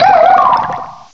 cry_not_frillish.aif